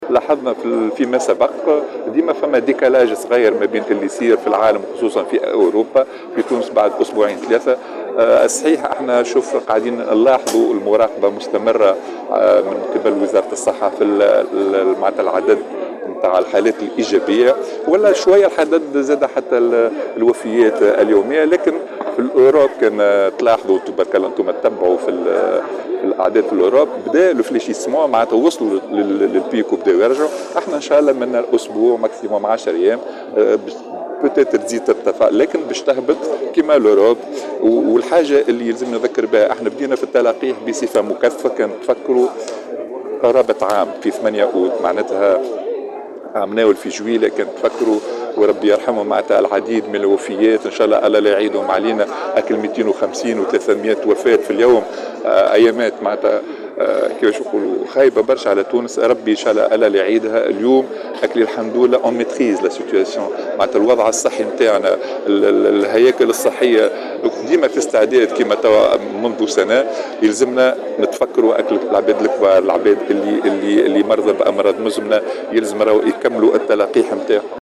وتوقع مرابط، في تصريح لمراسل الجوهرة اف ام، أن تشهد حالات الإصابة ارتفاعا في غضون أسبوع أو عشرة أيّام، ولكنها ستعود بعد ذلك الى الانخفاض مجدّدا، مشددا على ان الوضع الصحي لا يزال تحت السيطرة بفضل استعداد الهياكل الصحية، وارتفاع نسبة التلقيح ضد الفيروس في تونس.